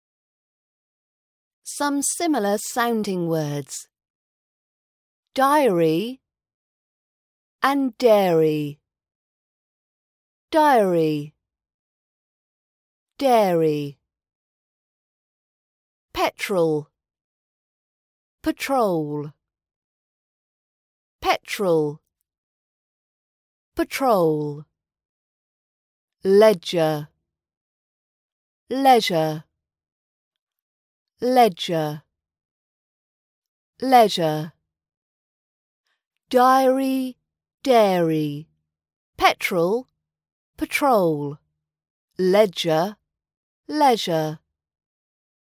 Some similar sounding words - British Accent Pronunciation practice
Short RP accent pronunciation practice podcast.